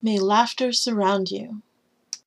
laughter.mp3